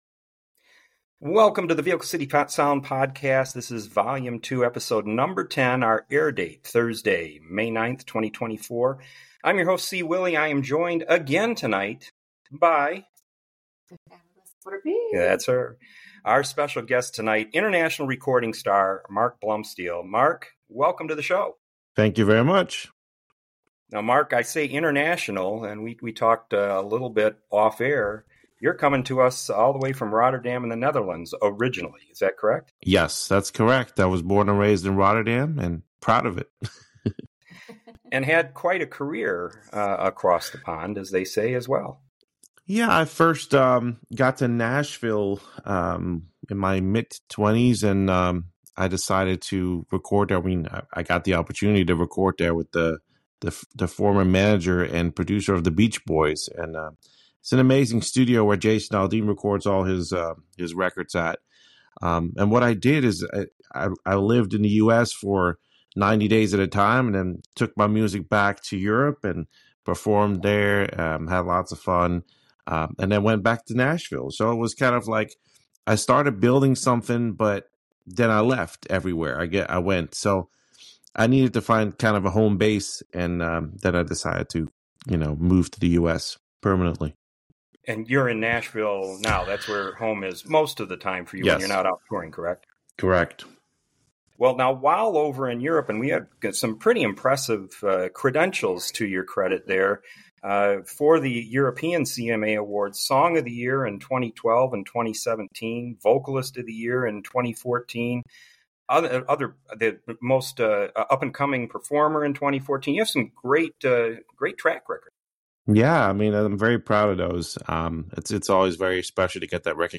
You'll love his upbeat country style!